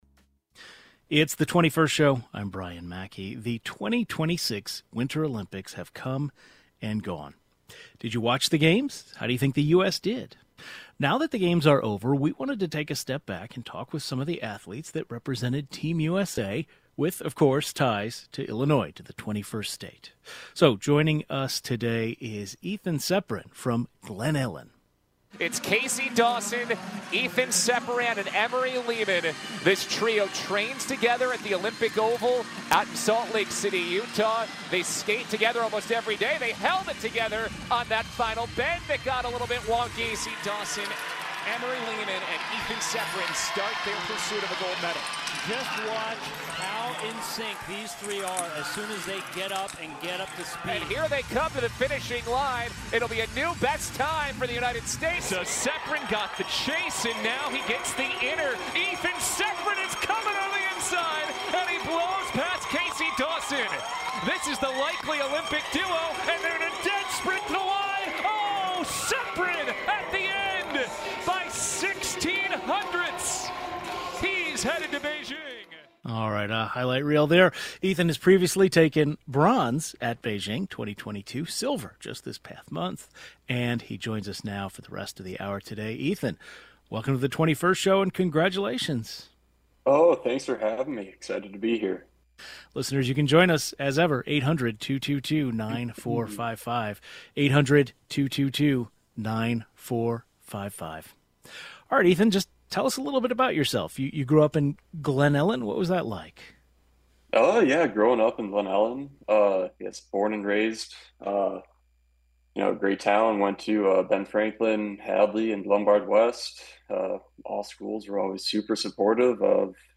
He joins the program today.